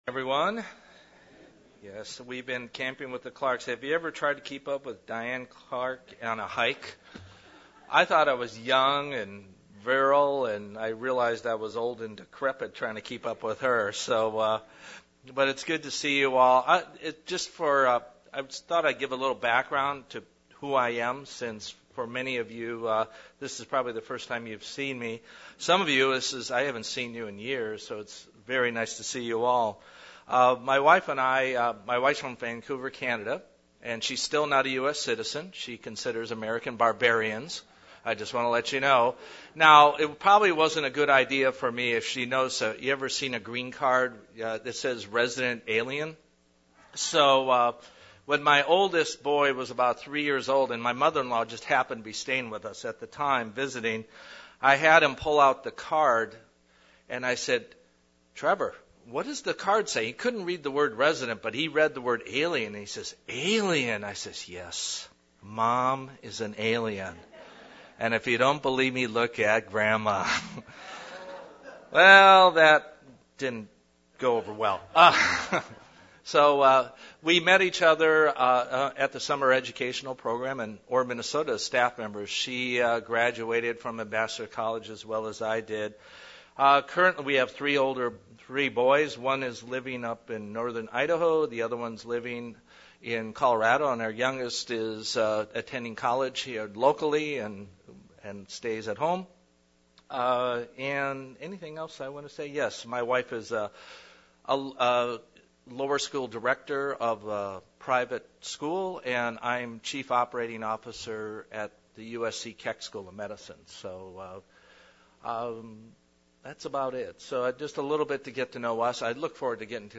Sermons
Given in San Diego, CA